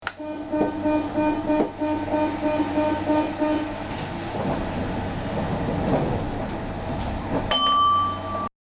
vibreur.mp3